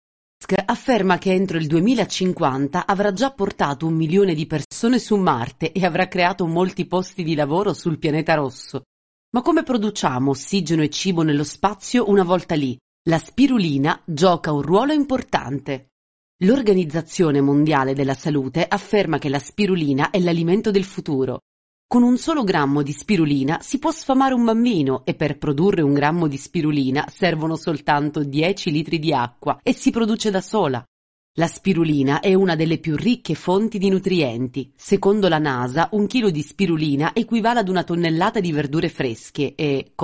微电影
温柔 活力 讲述 甜美 可爱